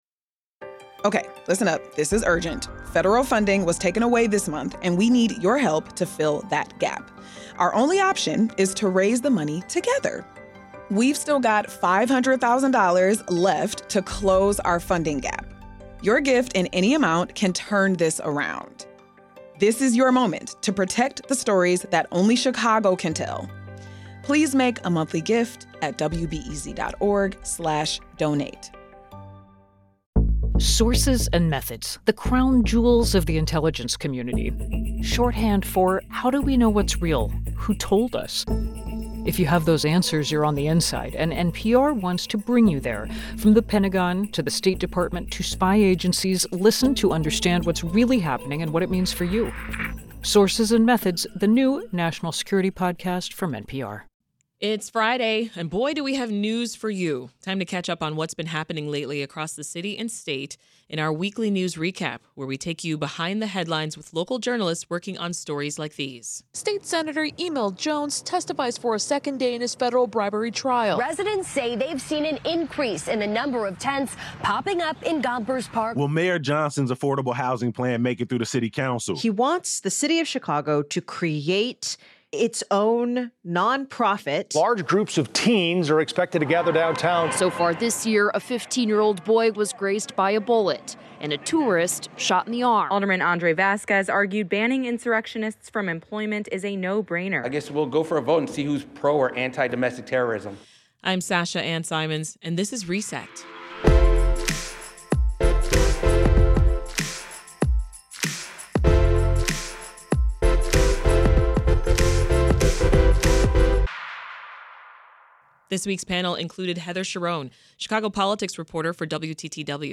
WBEZ’s Weekly News Recap, April 18, 2025